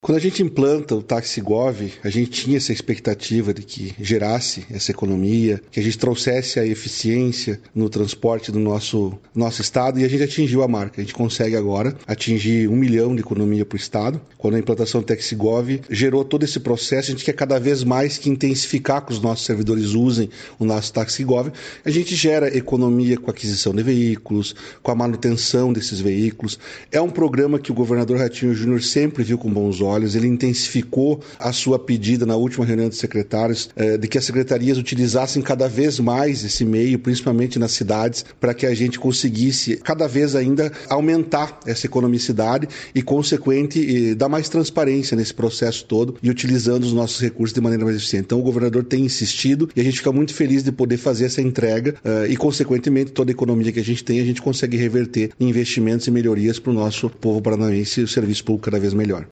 Sonora do secretário da Administração e Previdência, Elisandro Pires Frigo, sobre a economia de gastos públicos com o TaxiGov